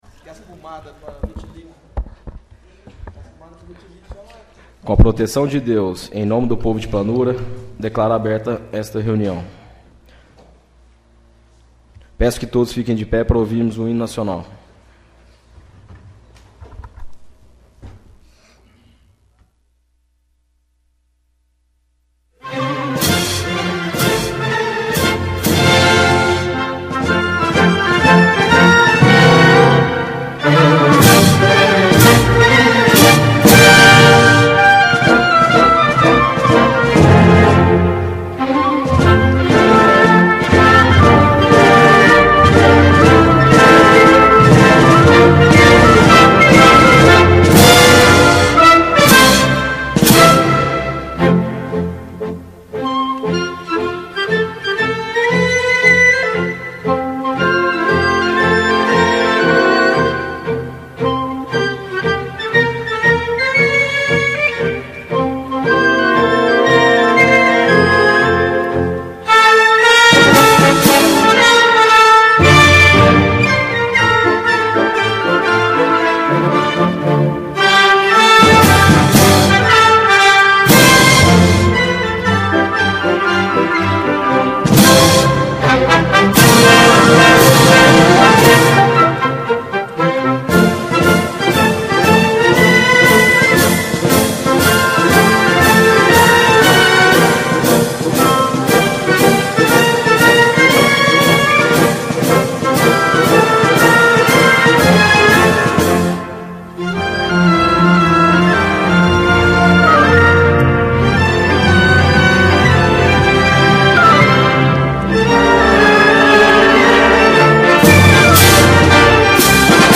Sessão Ordinária - 04/04/16 — CÂMARA MUNICIPAL DE PLANURA